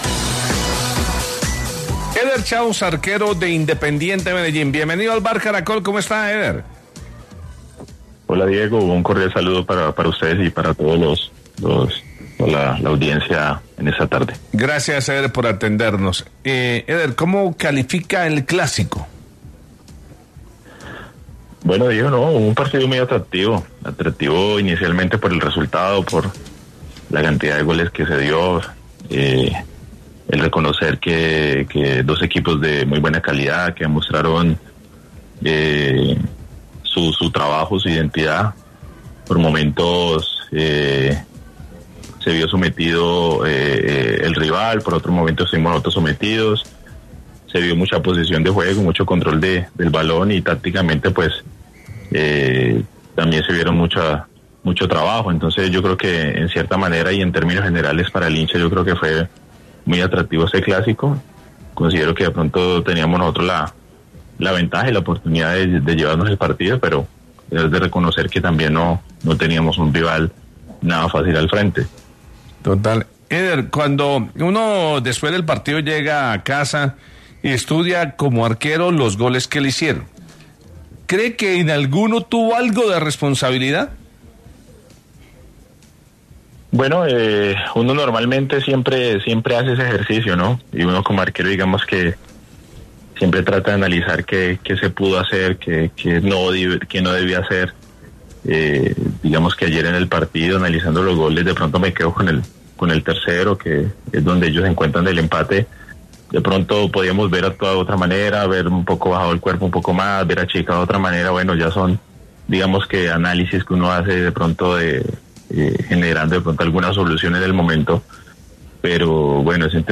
Éder Chaux, arquero de Independiente Medellín, le concedió una entrevista a El VBAR Caracol en la que analizó el rendimiento de su equipo tras al clásico paisa.